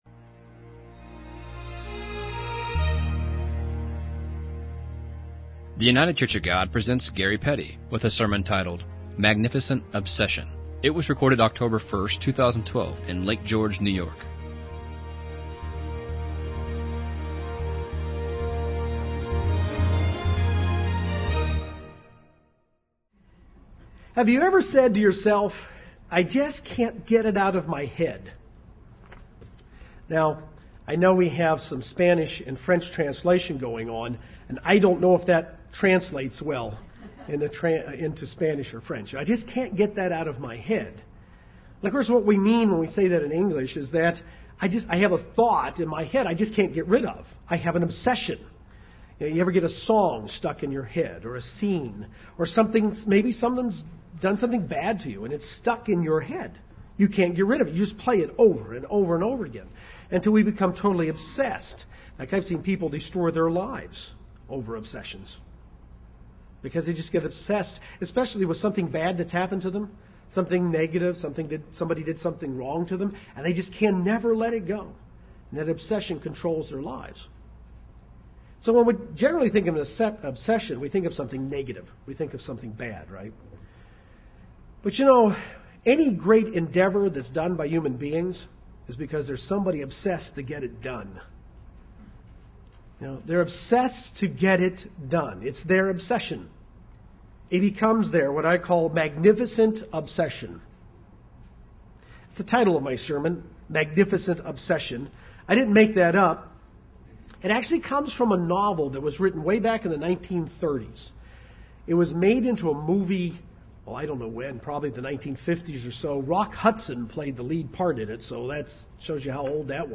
2012 Feast of Tabernacles sermon from Lake George, New York. Any great endeavor of mankind is the result of someone with a Magnificent Obsession.
This sermon was given at the Lake George, New York 2012 Feast site.